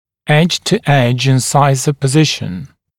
[eʤ-tu-eʤ ɪn’saɪzə pə’zɪʃn] [эдж-ту-эдж ин’сайзэ пэ’зишн] положение смыкания резцов режущими краями, прямое смыкание